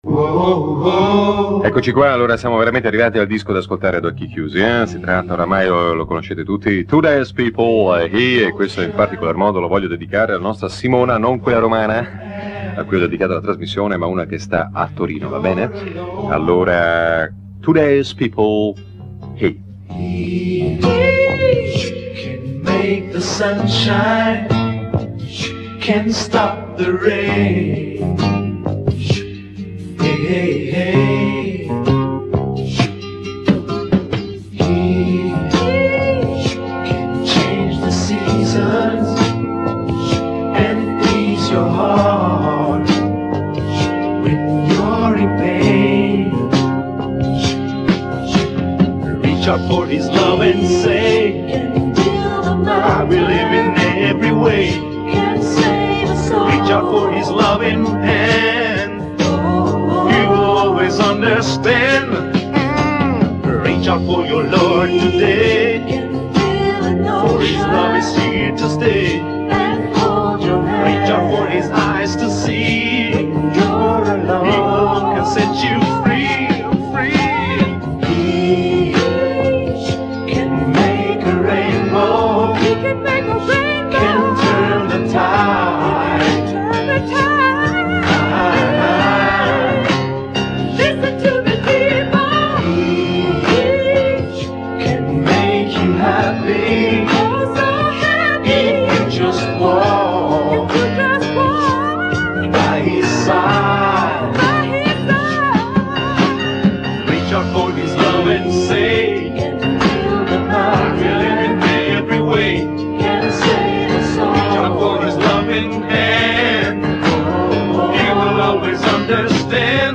I brani musicali sono sfumati per le solite esigenze di tutela del copyright.